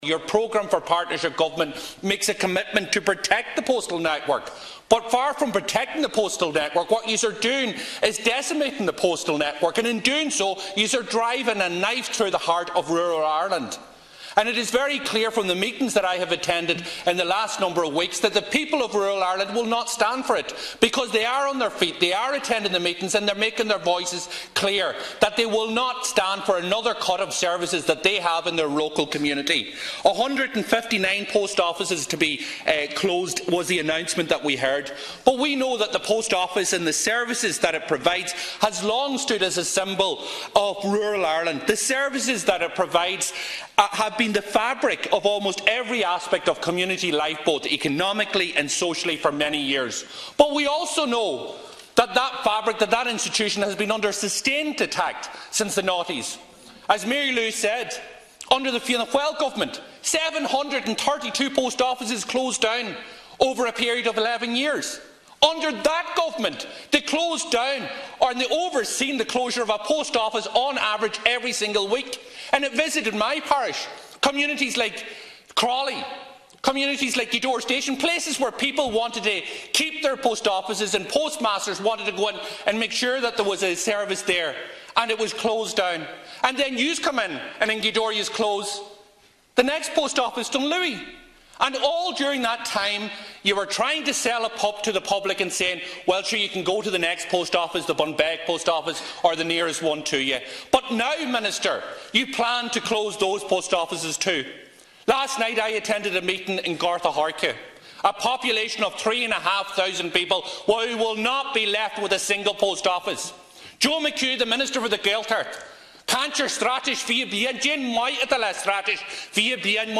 Speaking in the Dail last night Donegal Deputy Pearse Doherty outlined how that has affected Donegal: